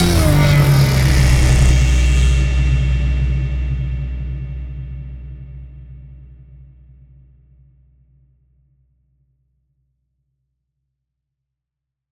death_sound.wav